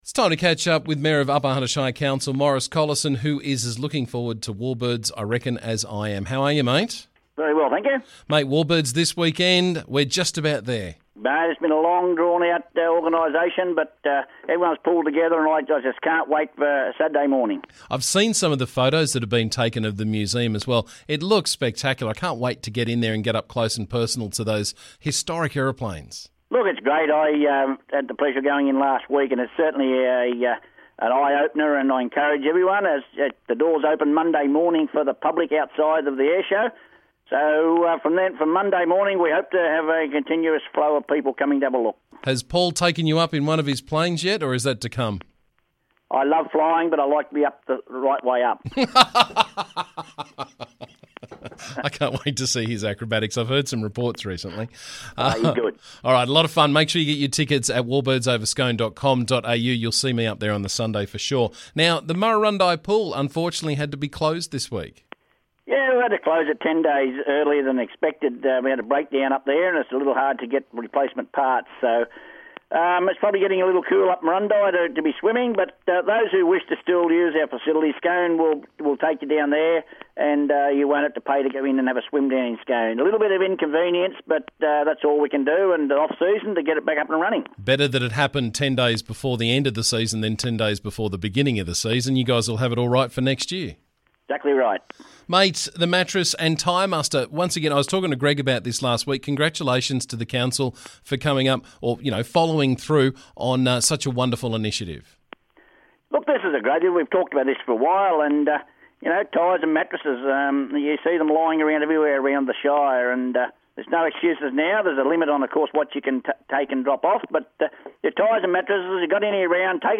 UHSC Mayor Maurice Collison was on the show this morning to keep us up to date with what's doing around the district.